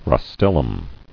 [ros·tel·lum]